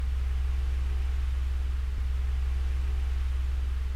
The gap interrupts the audio with a click sound.
It is a slight dud, but it still bothers me.